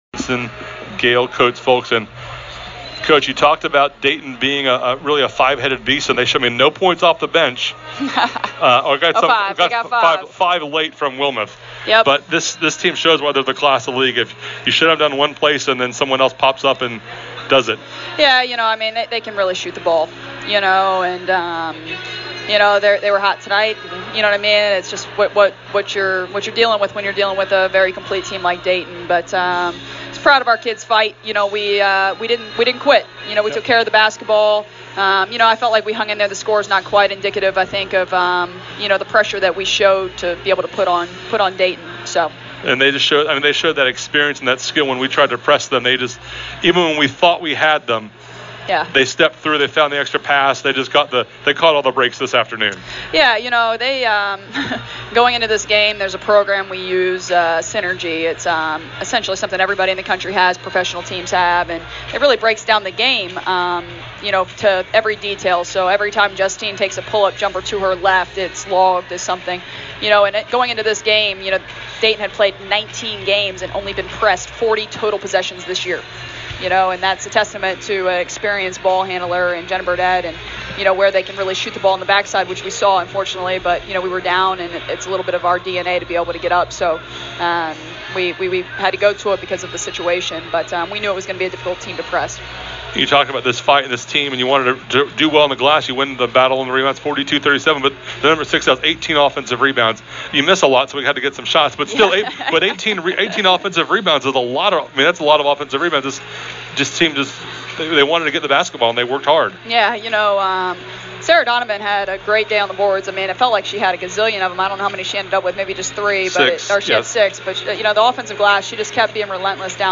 Postgame Comments
Post Game Dayton WBB.mp3